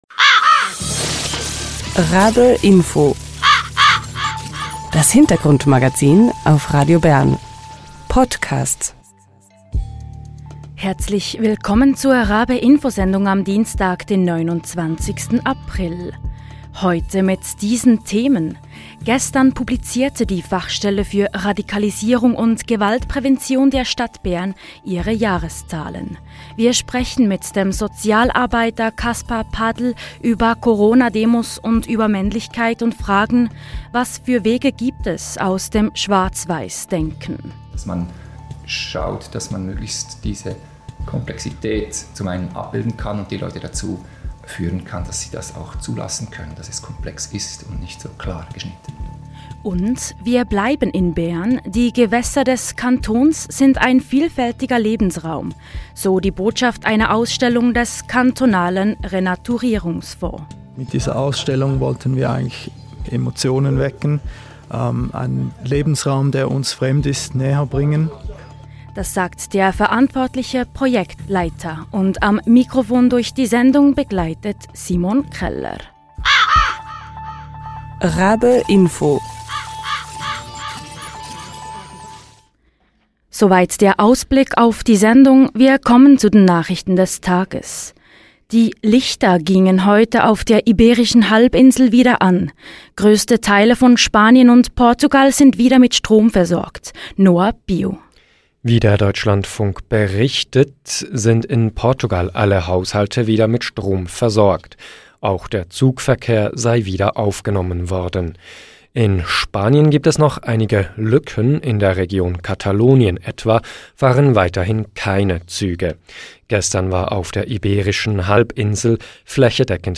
RaBe-Info war vor Ort.